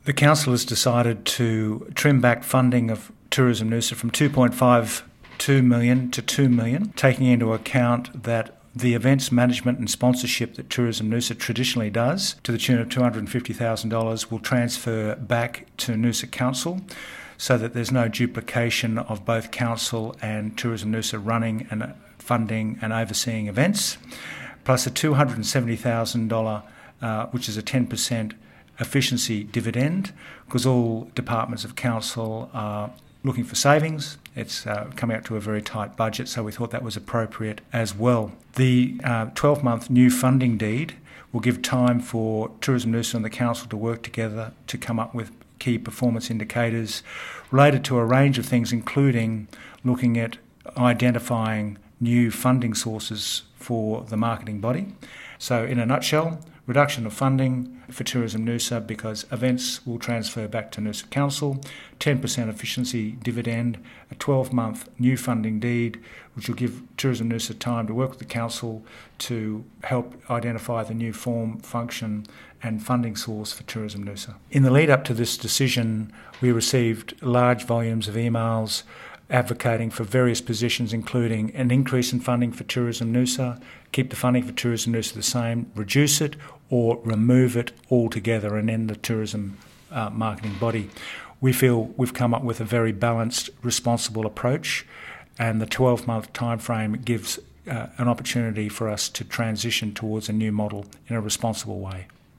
Noosa Mayor Frank Wilkie discusses Council's Tourism Noosa funding decision: